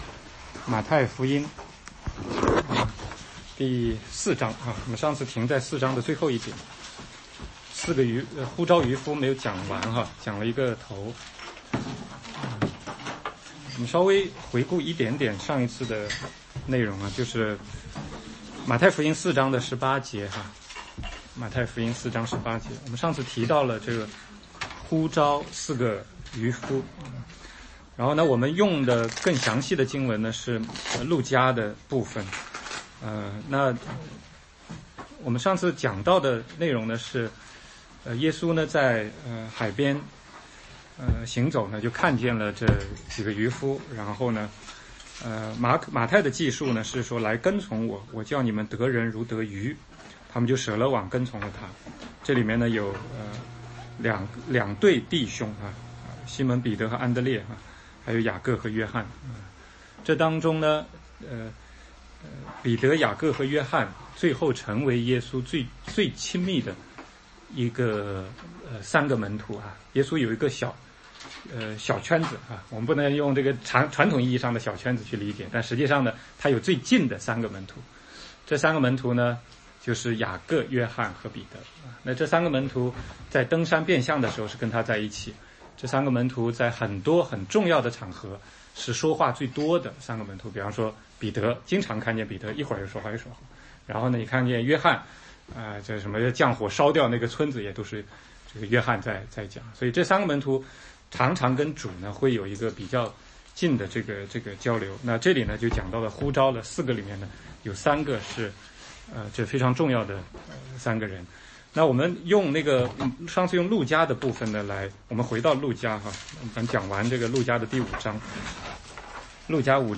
16街讲道录音 - 马太福音